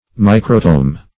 Microtome \Mi"cro*tome\ (m[imac]"kr[-o]*t[=o]m), n. [Micro- +